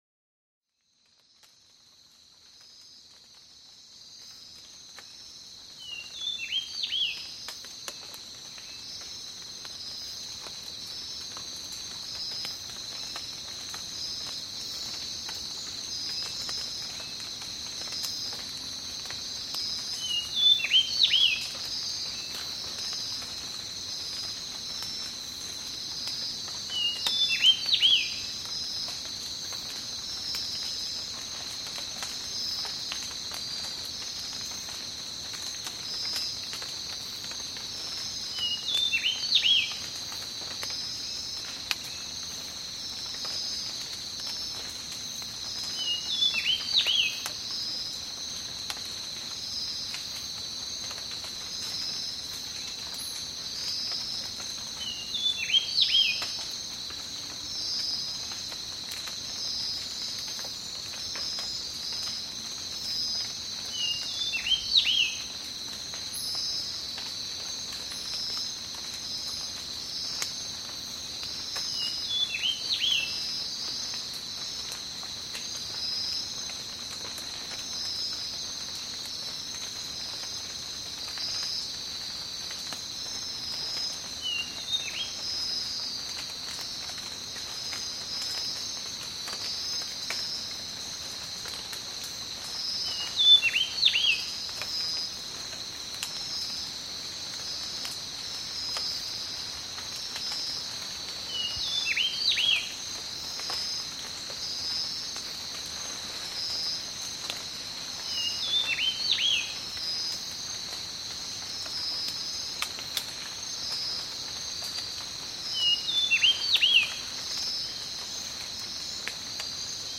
3D spatial surround sound "Tropical rainforest"
3D Spatial Sounds